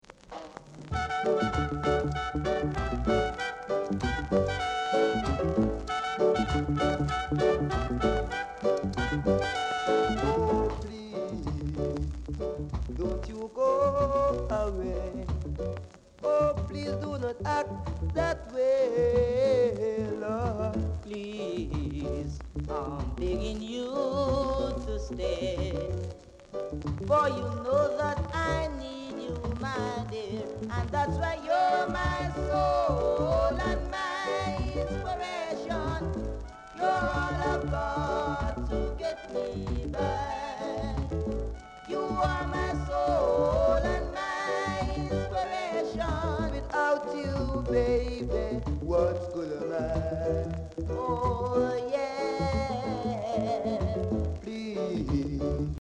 R.Steady Vocal Group